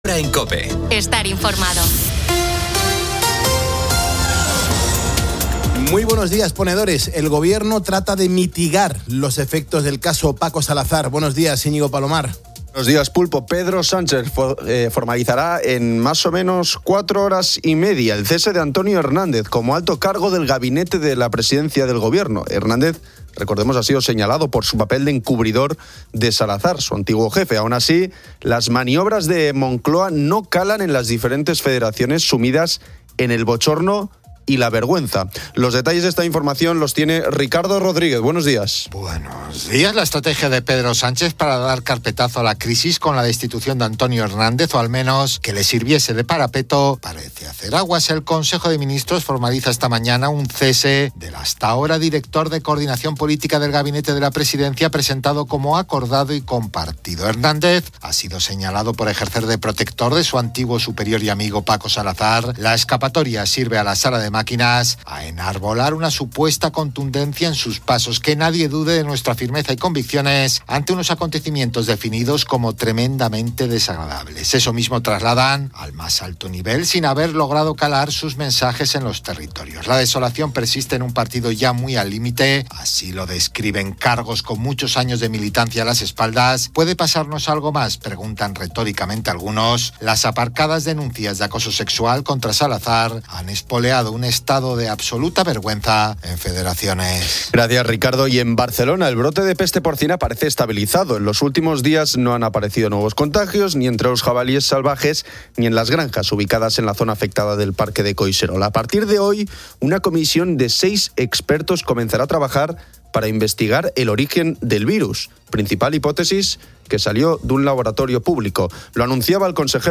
El programa discute los accidentes domésticos, que ocurren con frecuencia debido al exceso de confianza en casa. Los oyentes comparten experiencias que demuestran la importancia de la precaución, incluso en tareas cotidianas como cortar jamón.